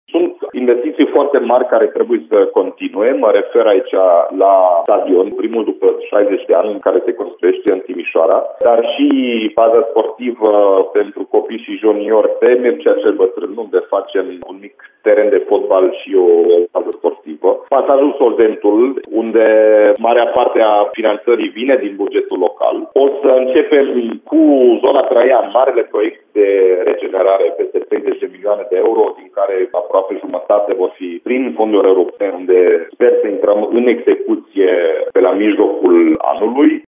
Primarul Timișoarei, Dominic Fritz, spune că printre priorități se află proiectele aflate în lucru cum ar fi stadionul Lego sau pasajul Solventul.